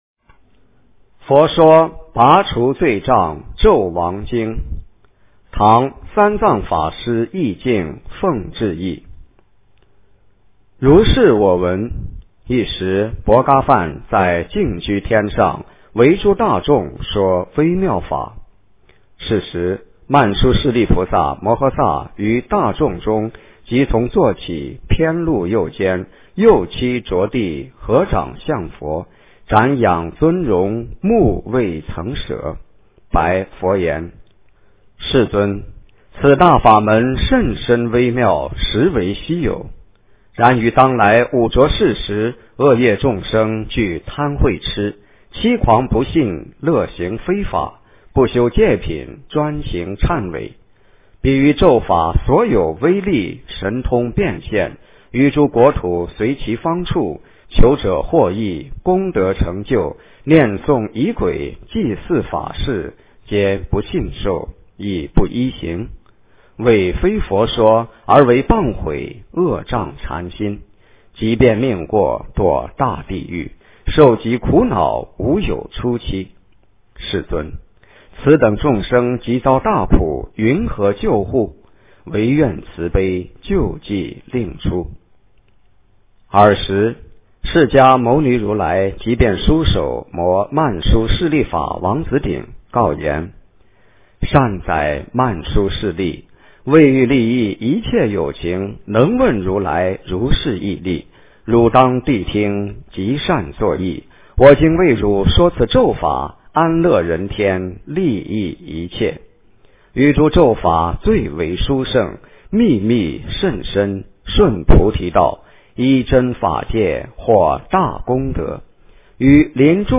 佛说拔除罪障咒王经 - 诵经 - 云佛论坛